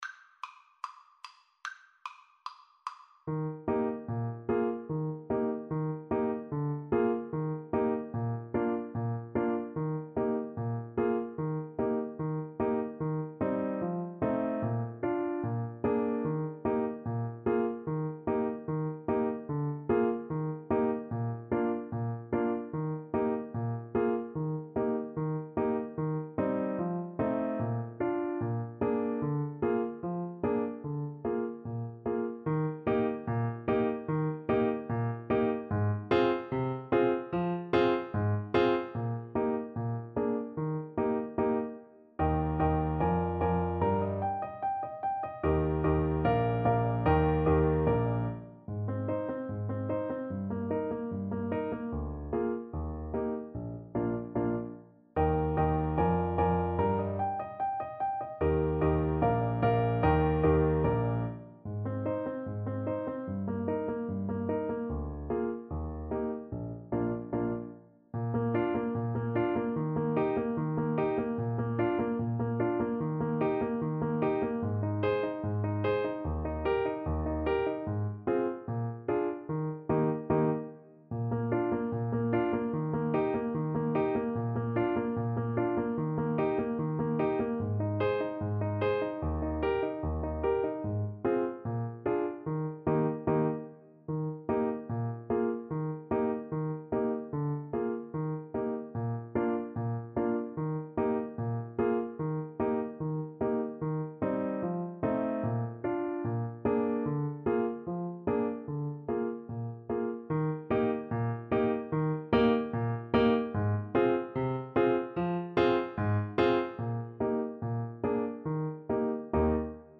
• Piano
Eb major (Sounding Pitch) F major (Trumpet in Bb) (View more Eb major Music for Trumpet )
Allegretto = 74
Classical (View more Classical Trumpet Music)